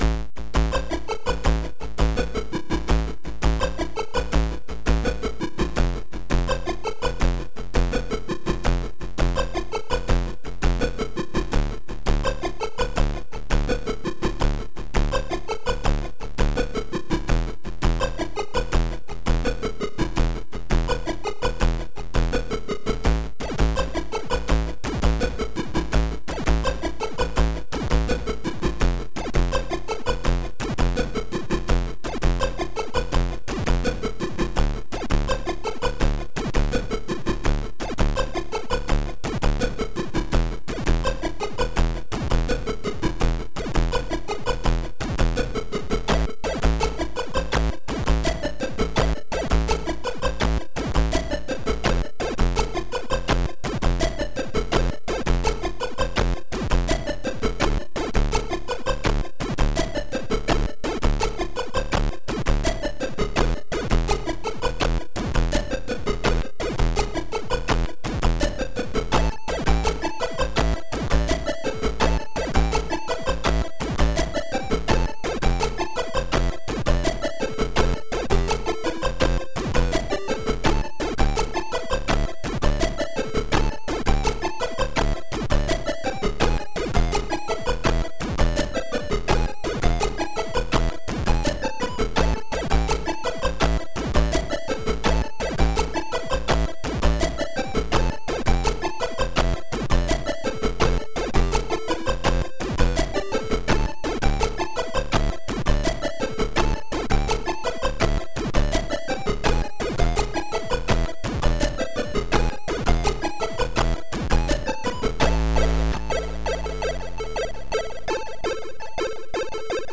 • Chip music
• Music is loop-able, but also has an ending